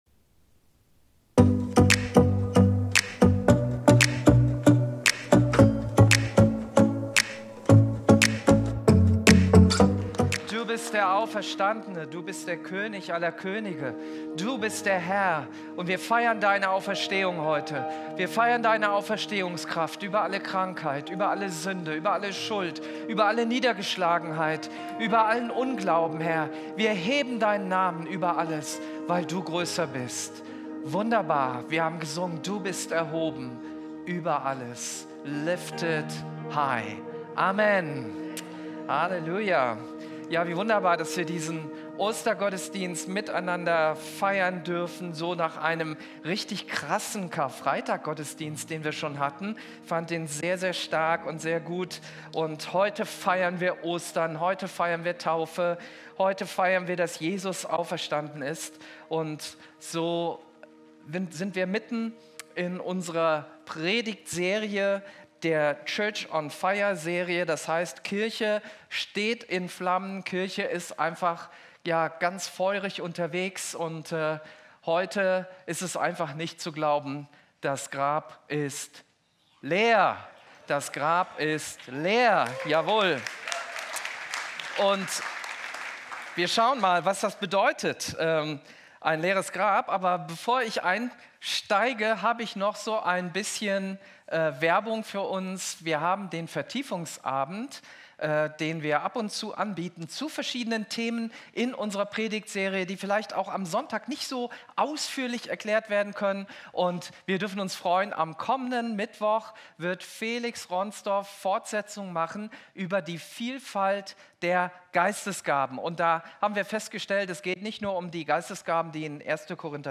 Video und MP3 Predigten
Kategorie: Sonntaggottesdienst Predigtserie: Church on fire